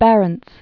(bărənts, bär-), Willem 1550?-1597.